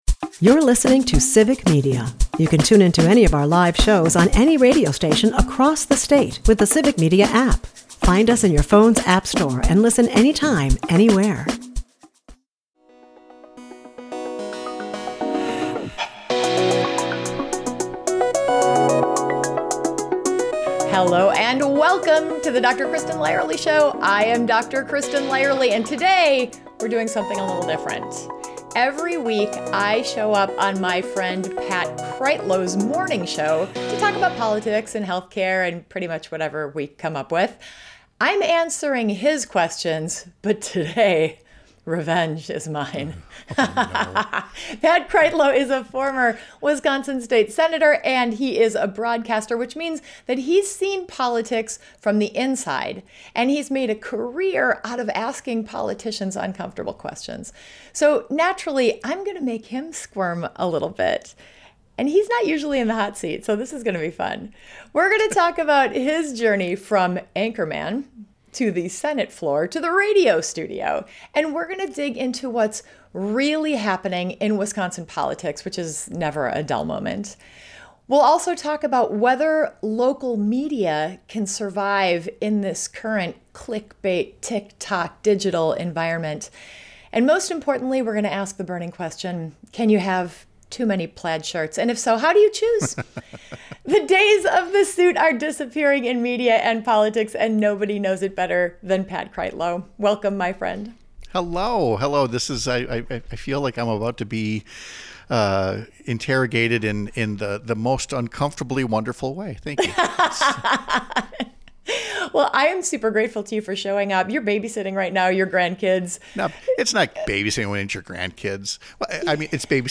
But this isn't your typical serious policy interview.